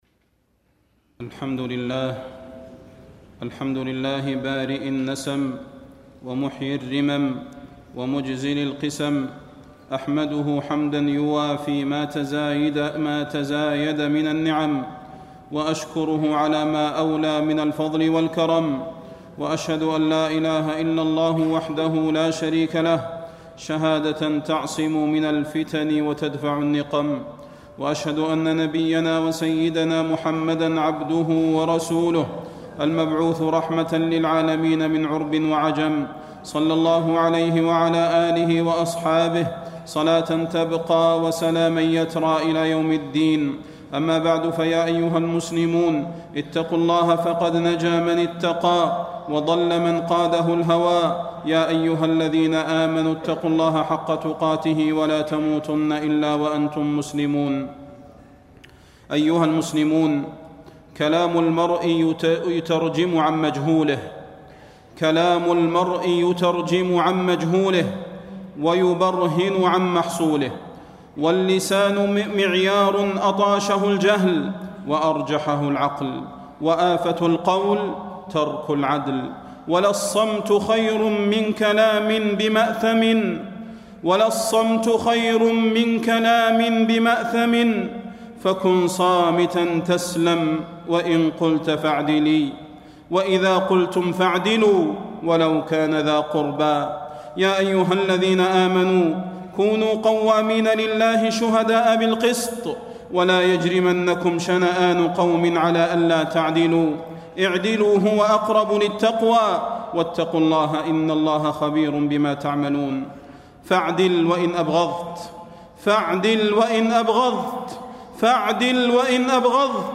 تاريخ النشر ٢٧ جمادى الآخرة ١٤٣٣ هـ المكان: المسجد النبوي الشيخ: فضيلة الشيخ د. صلاح بن محمد البدير فضيلة الشيخ د. صلاح بن محمد البدير آفة القول ترك العدل